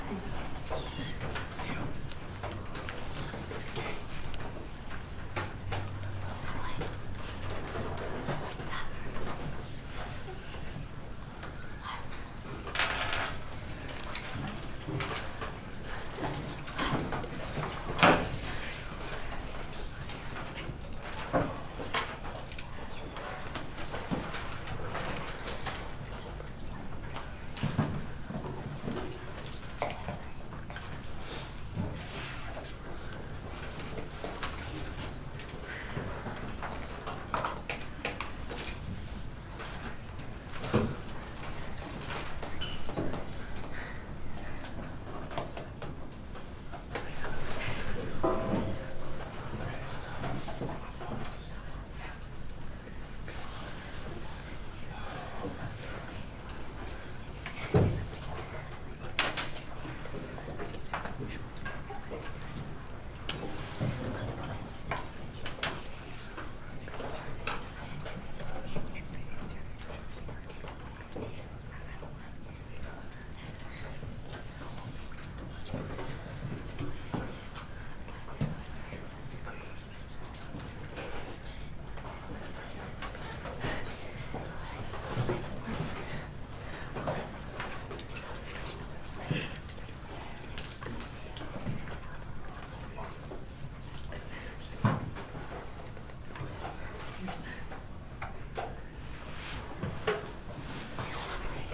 classroomWhisper01.mp3